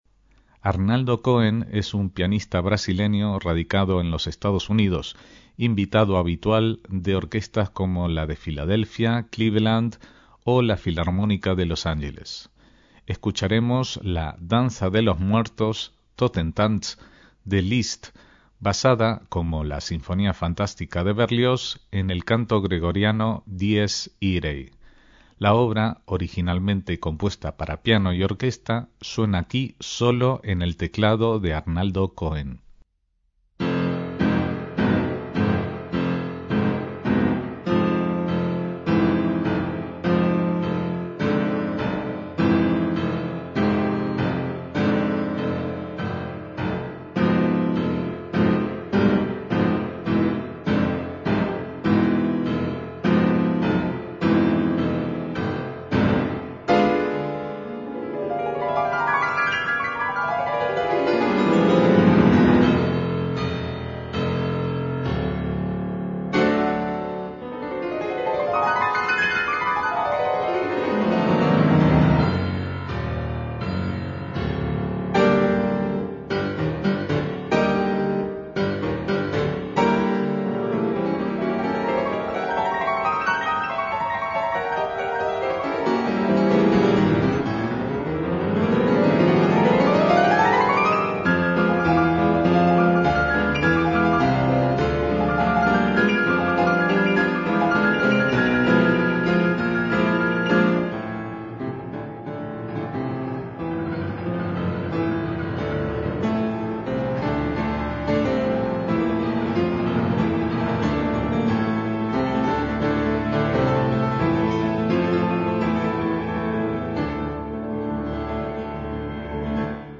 MÚSICA CLÁSICA - Arnaldo Cohen es un pianista brasileño nacido en 1948 y afincado en los EE.UU. En 1972 ganó el primer premio del Concurso Internacional de Piano Ferruccio Busoni.